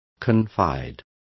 Complete with pronunciation of the translation of confide.